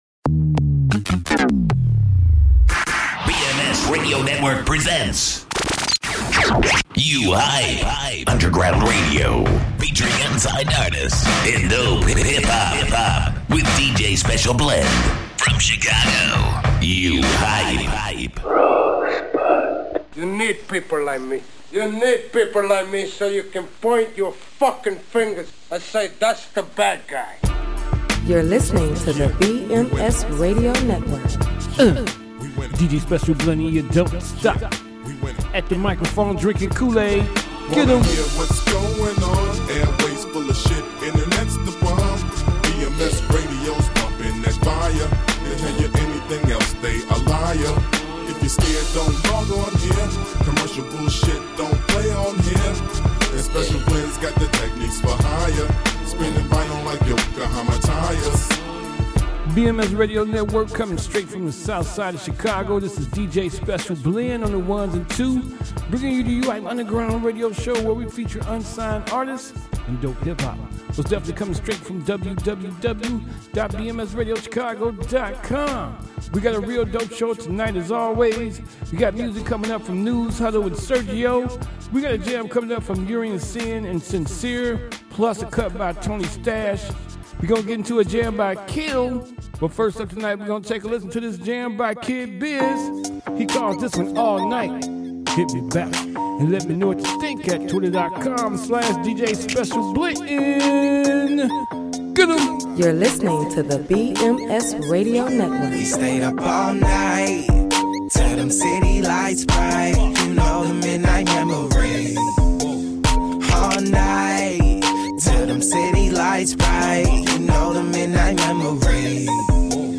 Chicago urban radio